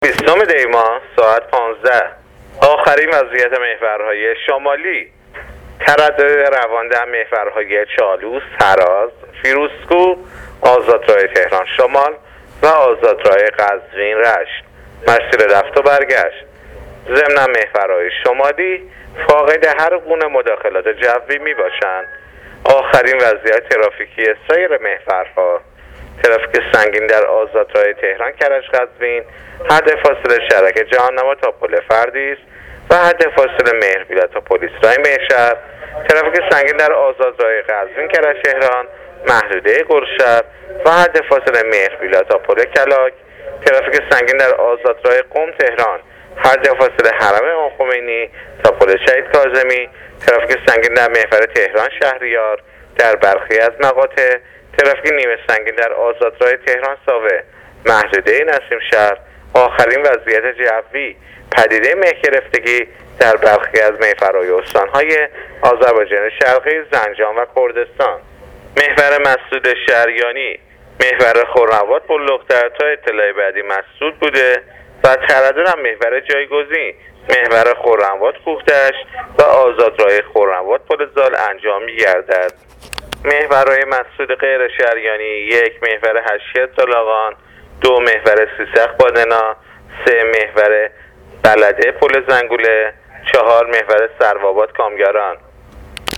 گزارش رادیو اینترنتی از آخرین وضعیت ترافیکی جاده‌ها تا ساعت ۱۵ بیستم دی؛